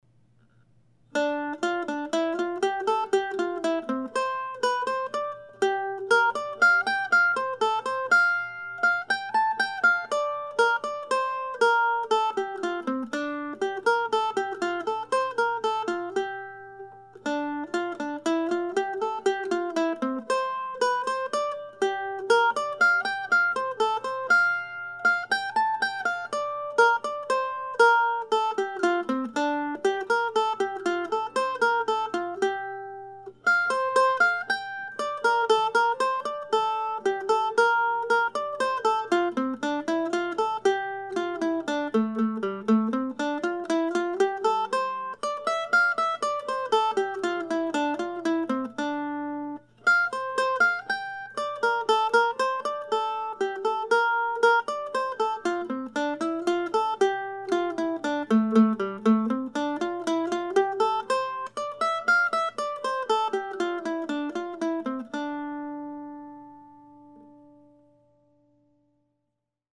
Early in 2016 I started writing short pieces modeled after the Divertimentos that James Oswald composed and published in the 1750s in London.
I've been playing them before or after Oswald's own pieces during my solo mandolin coffee house gigs this year and now my plan is to turn them into a small book that I intend to have available at the Classical Mandolin Society of America annual convention in Valley Forge, early next month.